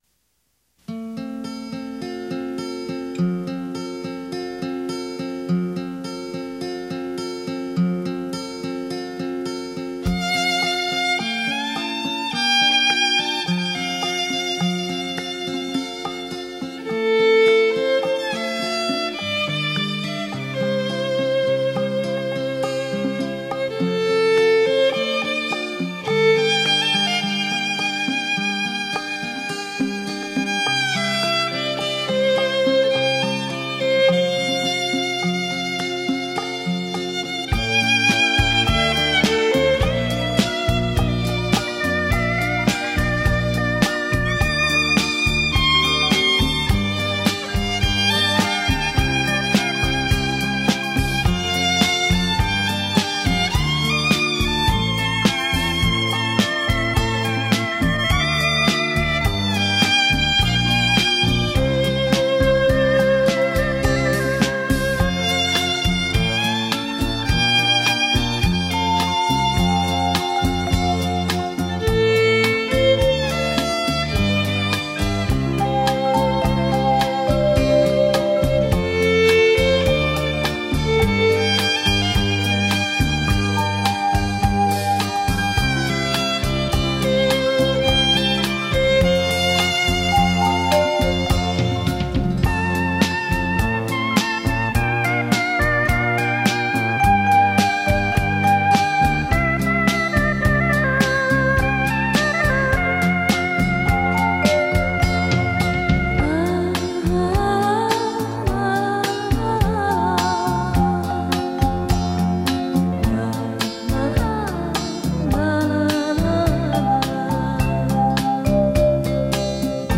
小提琴曲”《海鸥飞处